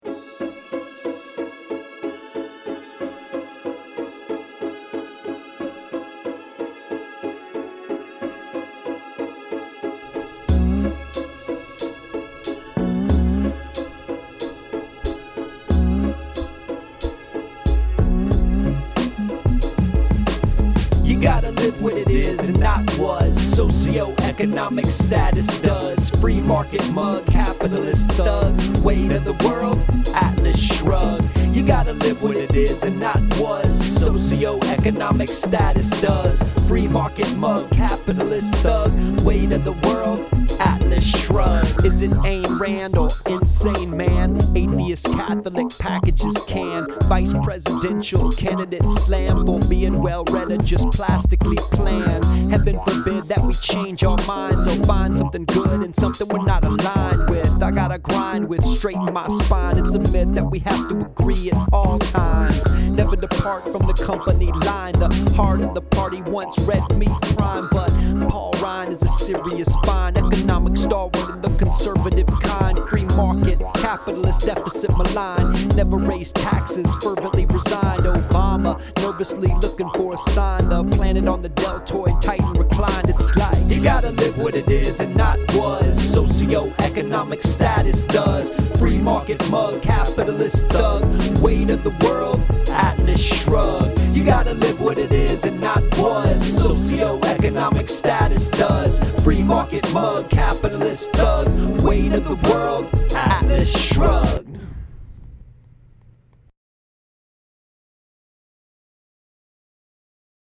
On the road. Early hours of the morning, recording from a top secret undisclosed vocal booth. About to pass out.
Left my windscreen at home, of course. Had to use a Kleenex on my mic to try and kill some of the consonants.
I haven’t heard the rap yet, but it’s absolutely ridiculous that this is the kind of stuff Republicans have to come to terms with